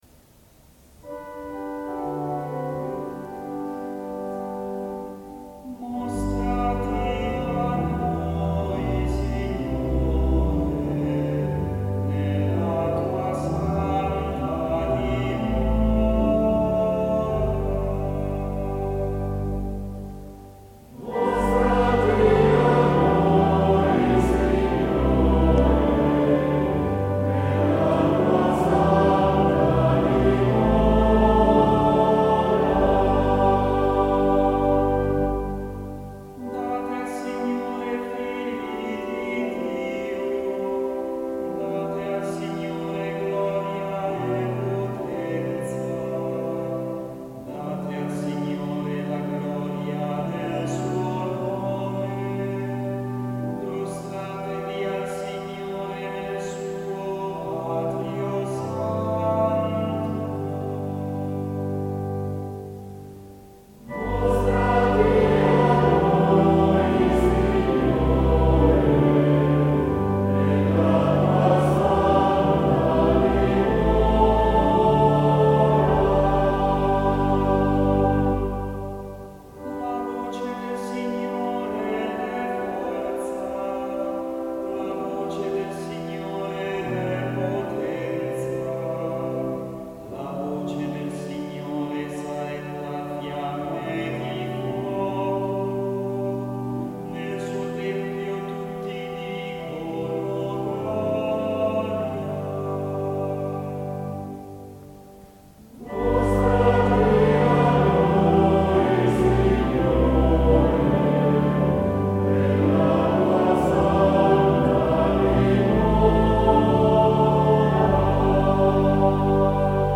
Audio Esecuzione Corale S. Vittore – Varese